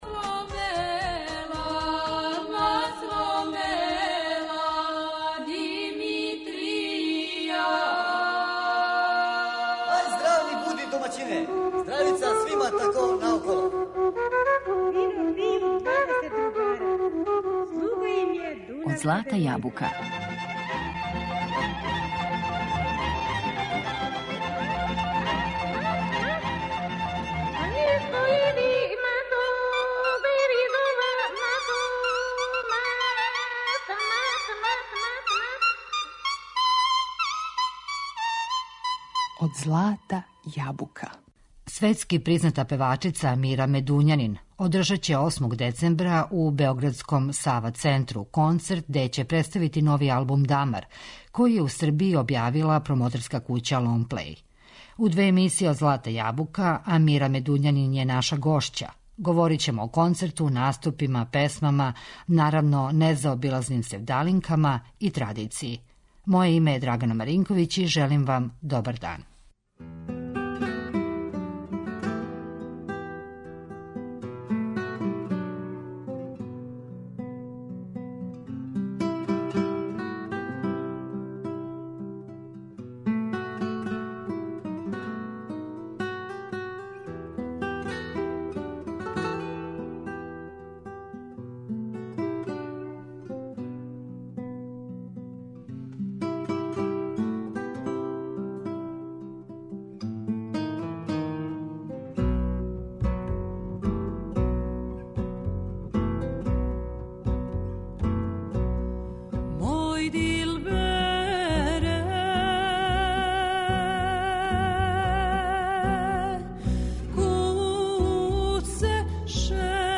Гост је Амира Медуњанин